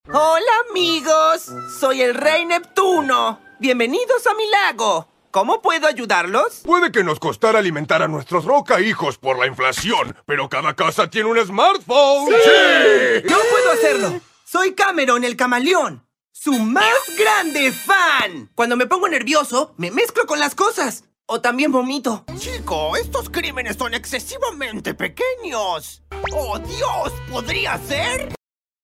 Actor de doblaje · Locutor
Animación
animacion.mp3